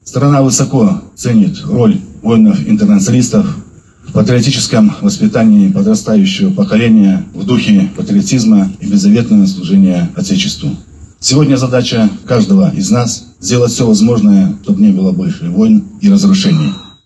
В Барановичах у памятника воинам-интернационалистам собрались ветераны-афганцы, члены их семей, матери и вдовы погибших, руководство города, представители общественных организаций, предприятий, силовых структур, военнослужащие, духовенство, молодежь, жители города.